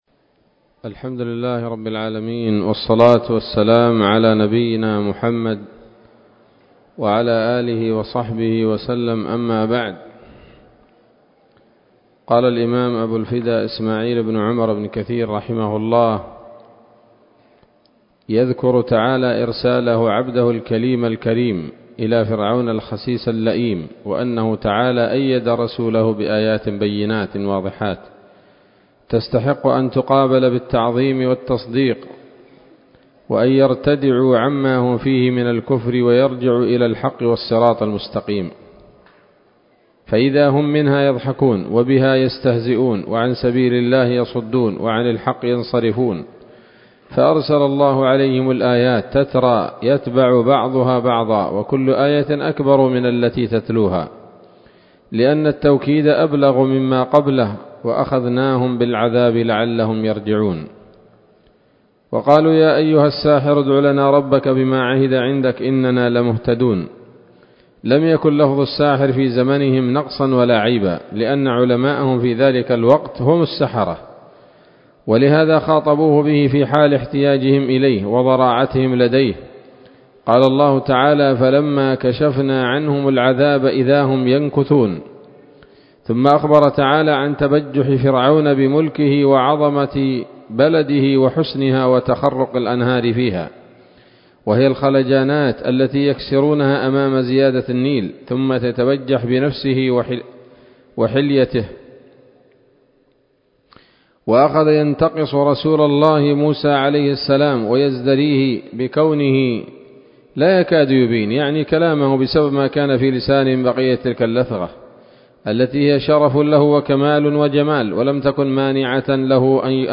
‌‌الدرس الثاني والتسعون من قصص الأنبياء لابن كثير رحمه الله تعالى